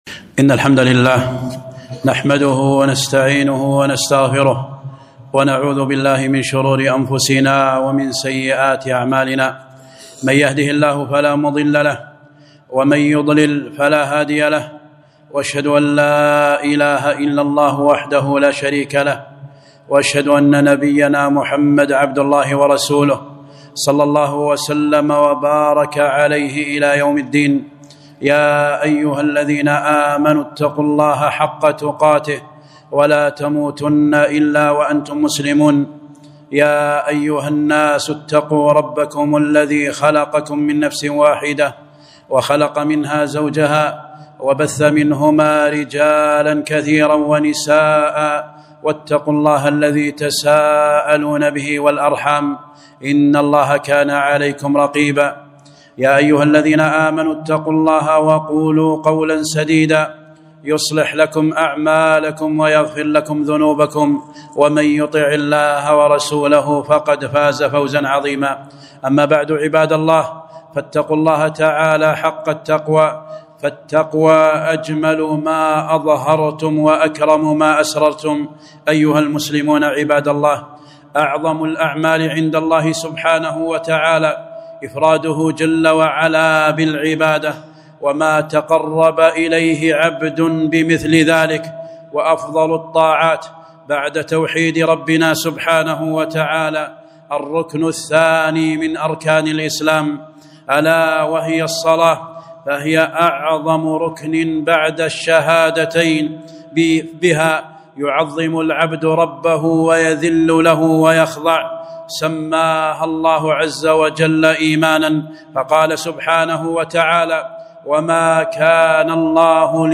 خطبة - ( الصلاة.. الصلاة.. وما ملكت أيمانكم)